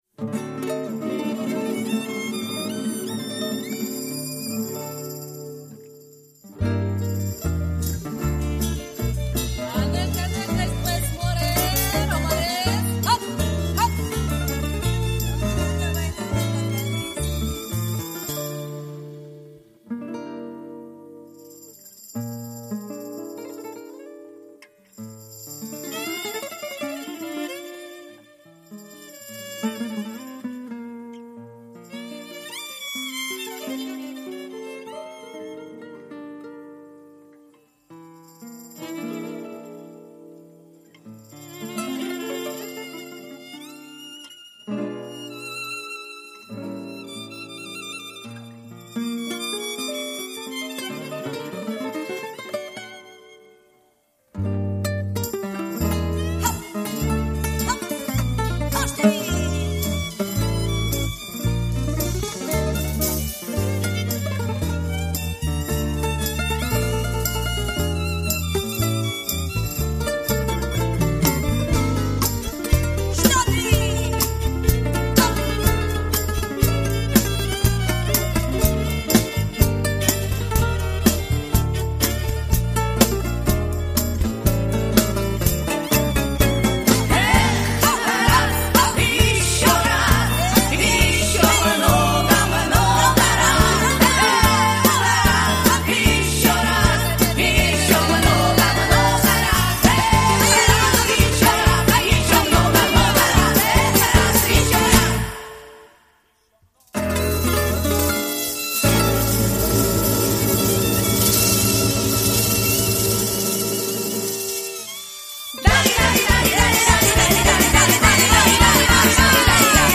прекрасная цыганская мелодия без слов